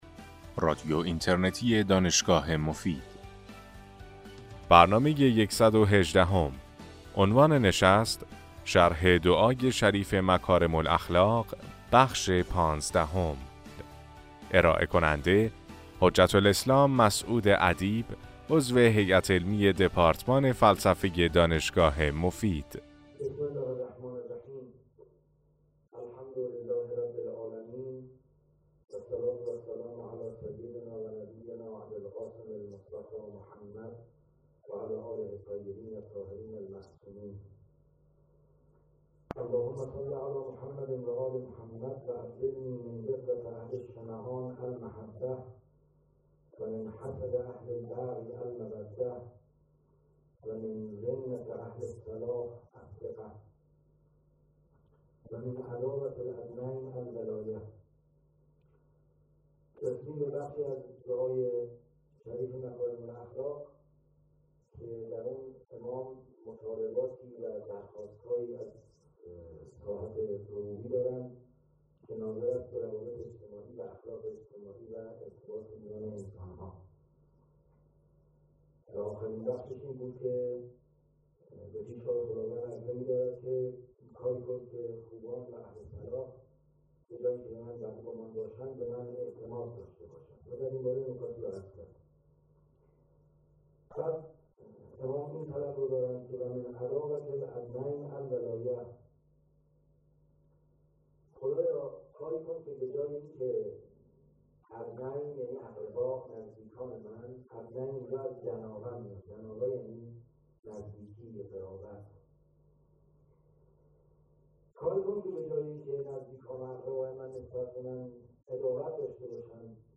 سلسله سخنرانی